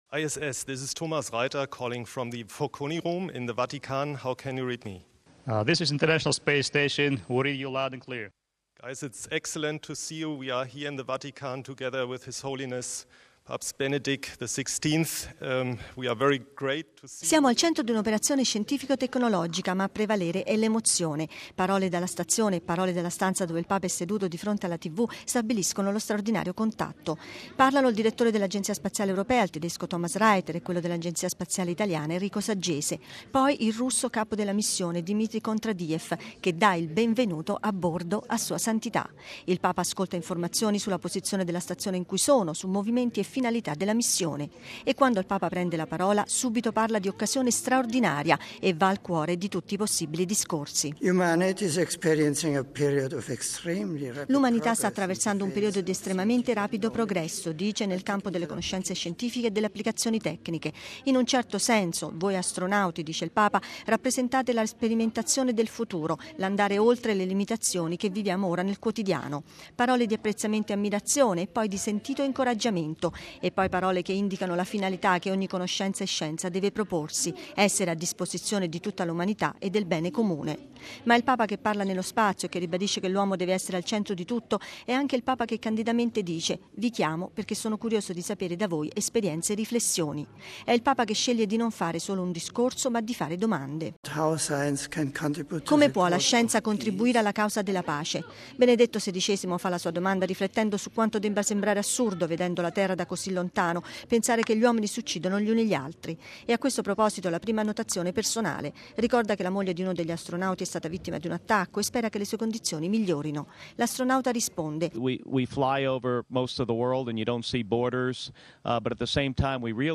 (parole di apertura collegamento con la stazione spaziale)
Contribuisce a creare l’emozione di una situazione così particolare il ritardo di circa 5 secondi sul suono.